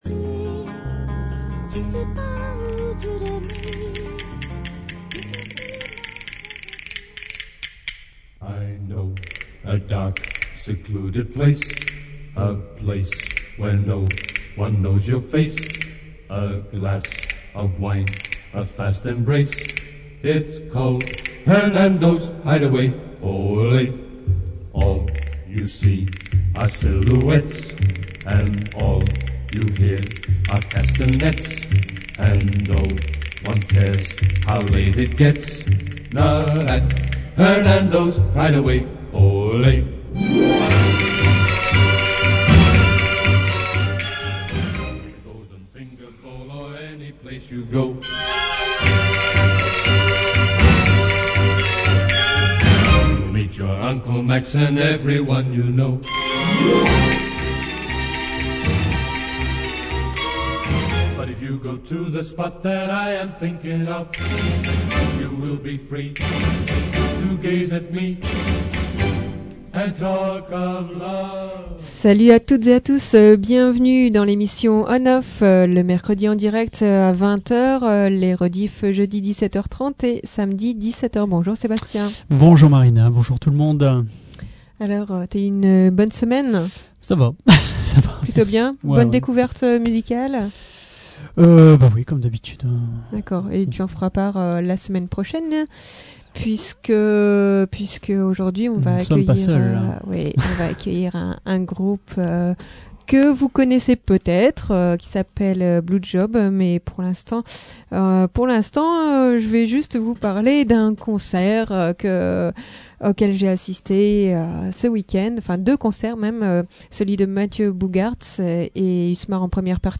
Blew Job : un groupe de reprises :